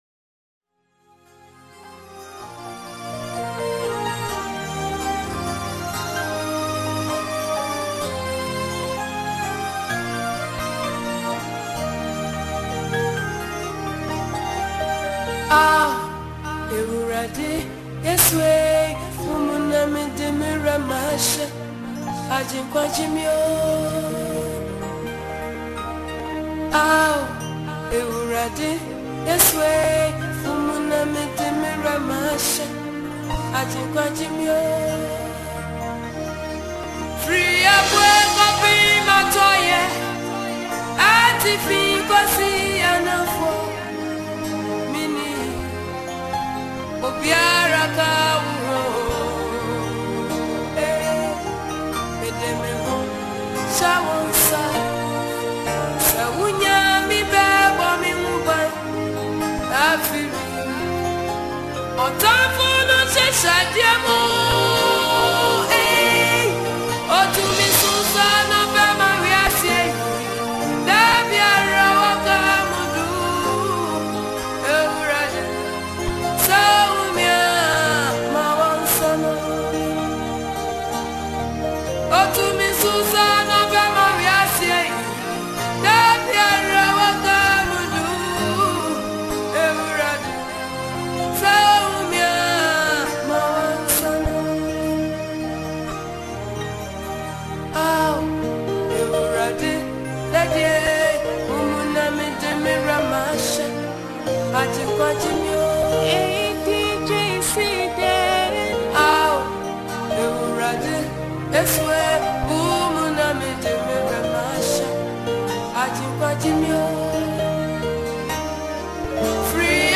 known for her inspiring and uplifting music.
Genre: Mixtape